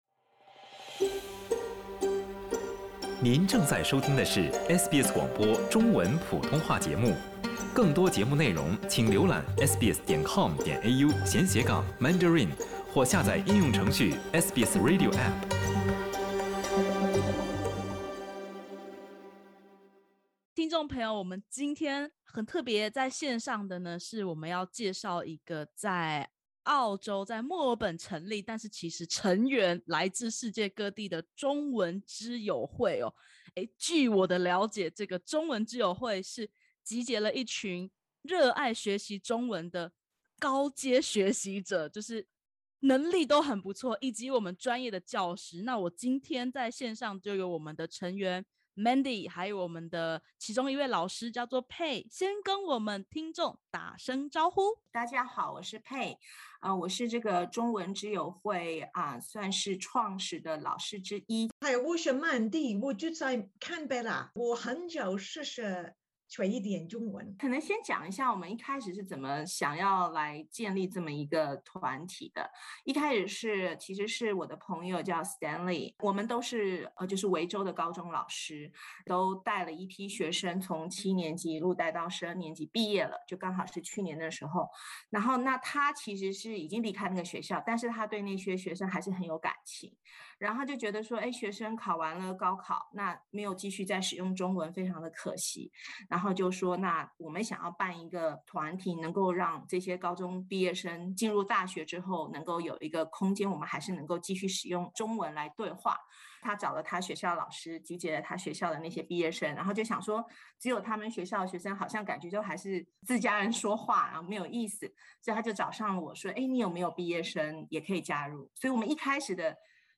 由墨尔本一群华人背景的教师，志愿发起中文之友会，邀集高阶中文学习者们每周云端相会。点击首图收听采访音频。